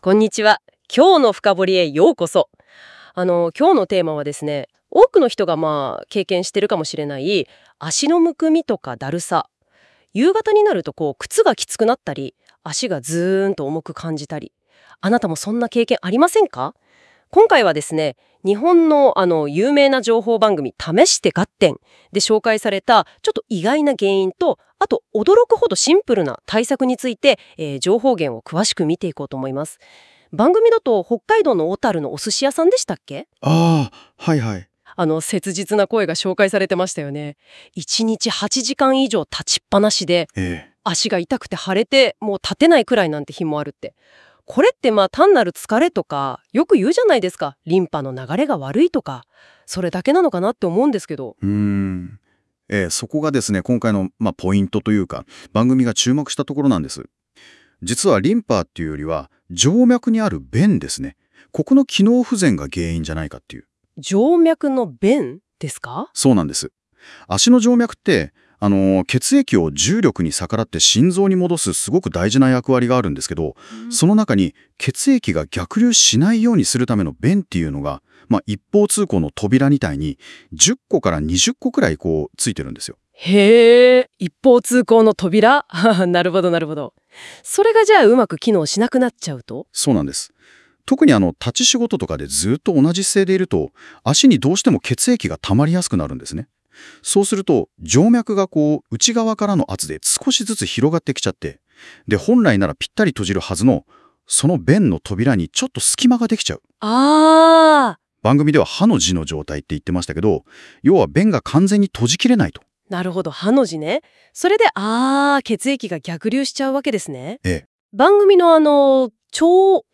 画面には、元気よく「いらっしゃいませ！」と声をかけるお寿司屋さんの姿。
黒い布のようなものが映し出され、スタジオは騒然。
しかし、12／20人で“異音”ブシューッが！
このヘンテコな音、実は静脈弁の逆流音。